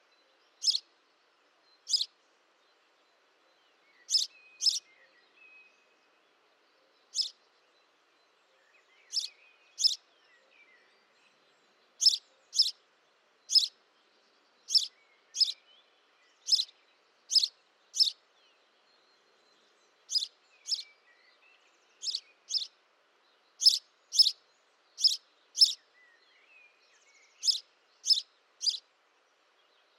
Photos de Moineau domestique - Mes Zoazos
moineau.mp3